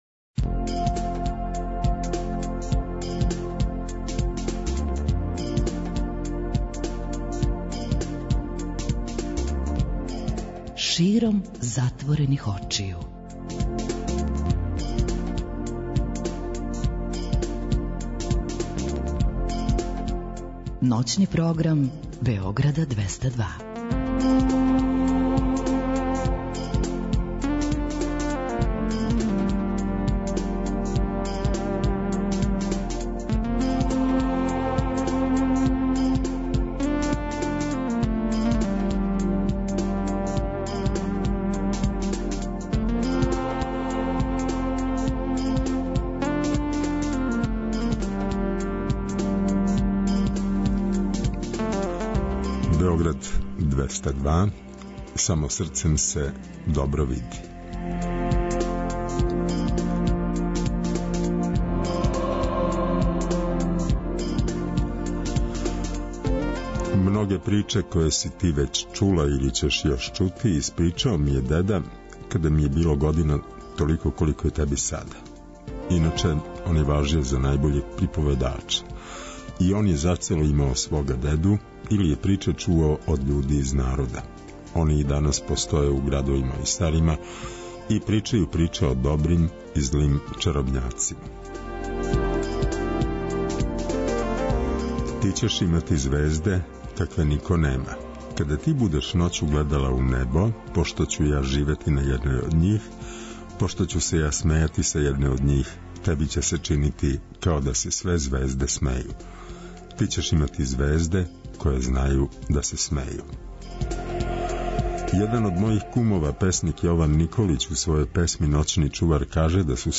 Ноћни програм Београда 202.
Тако некако изгледа и колажни контакт програм "САМО СРЦЕМ СЕ ДОБРО ВИДИ".